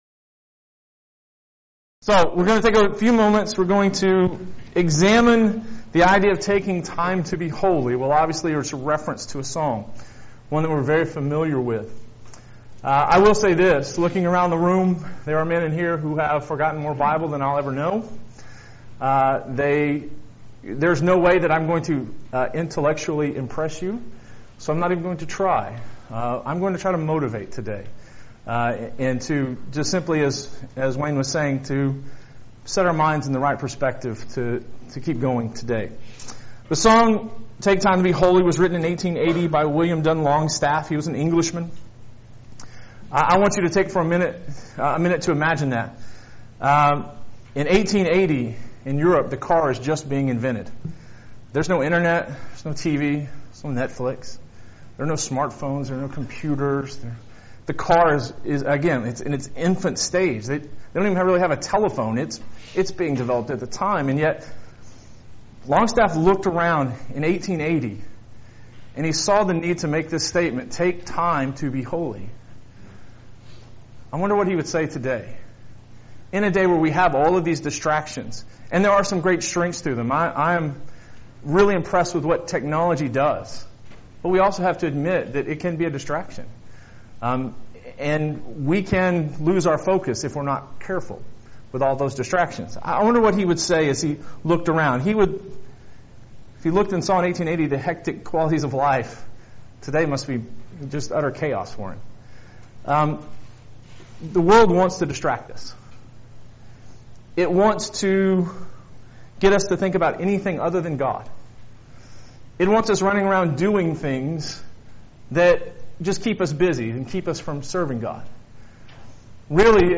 Preacher's Workshop
Song Study